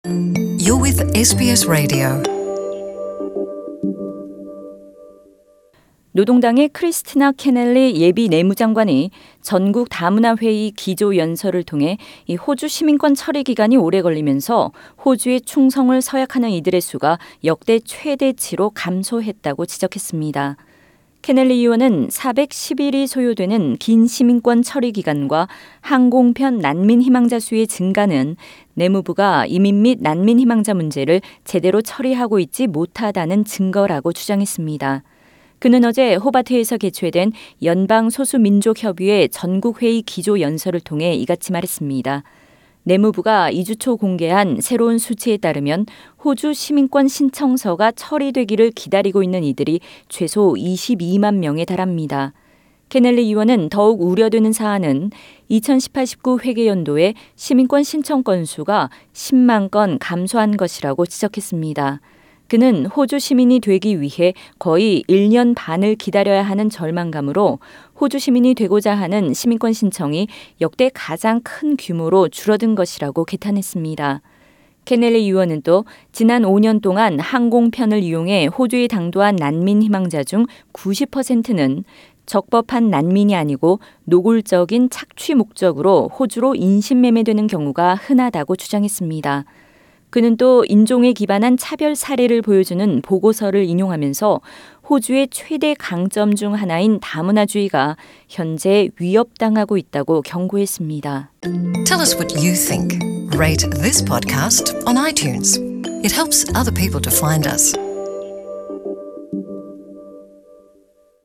Labor's spokesperson for immigration, Kristina Keneally, used a keynote speech to warn that multiculturalism is under threat.